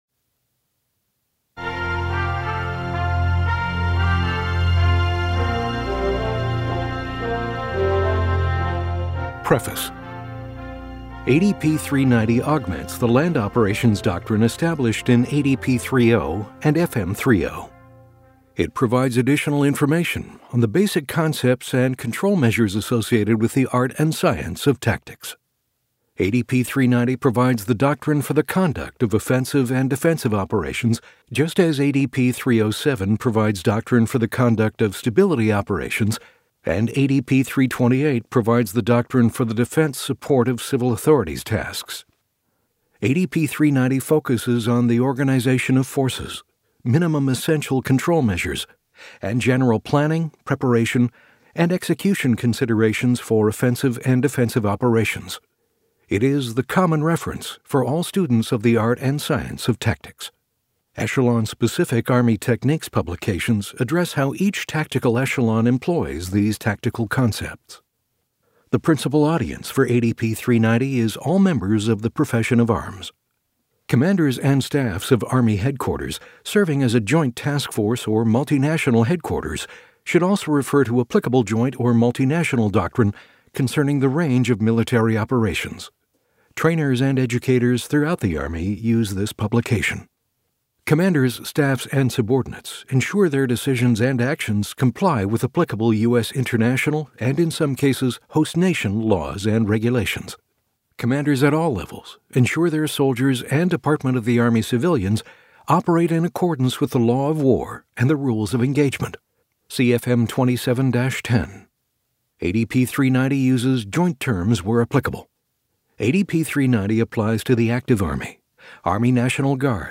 This is the download page for the Preface of Army Doctrine Audiobook of Army Doctrine Publication (ADP) 3-90, Offense and Defense
It has been abridged to meet the requirements of the audiobook format.